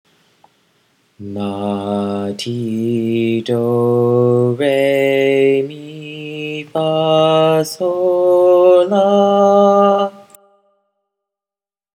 In la-based minor, the tonic shifts to la, so that a minor scale would be la ti do re mi fa so la.
minor-la.m4a